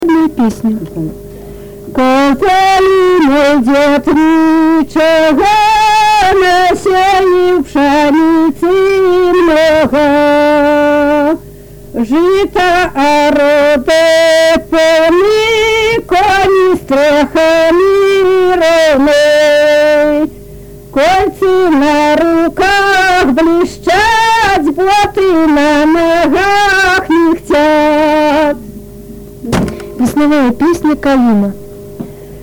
Тема: ЭБ БГУ::Беларускі фальклор::Каляндарна-абрадавыя песні::калядныя песні
Месца запісу: Узбішчы